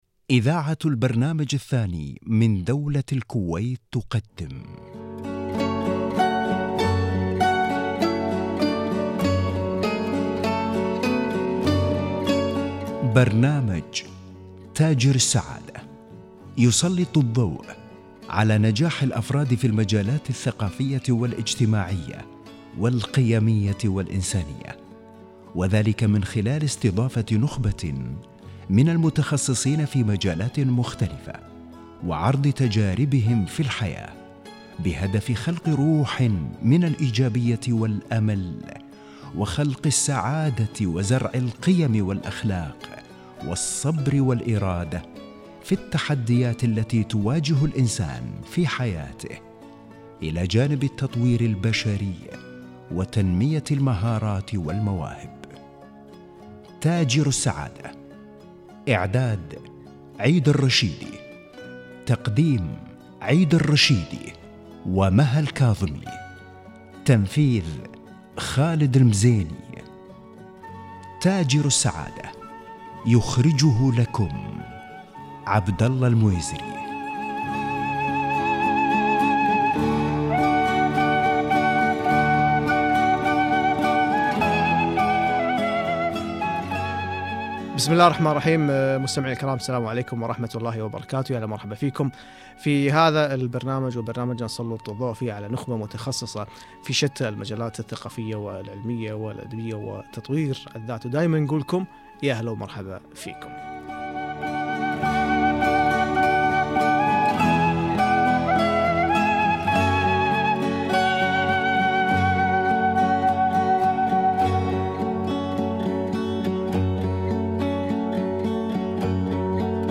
لقاء من برنامج تاجر السعادة الاذاعي